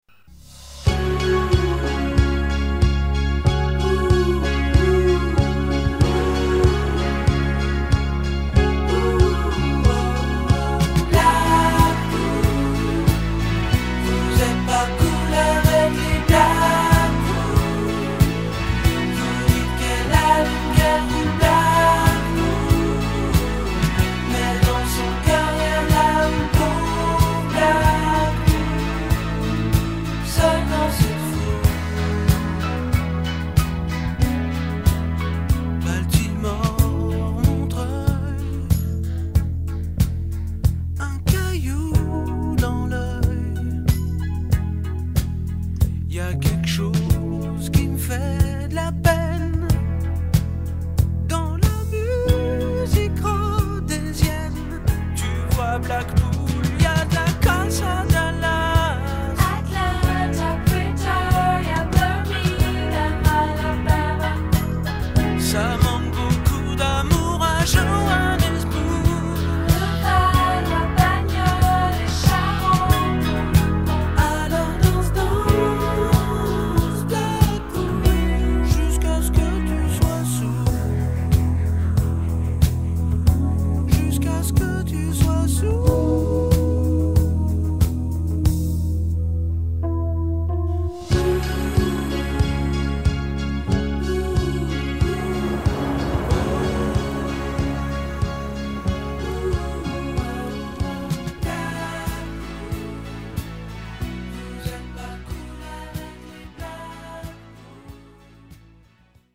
tonalité MI majeur